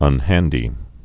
(ŭn-hăndē)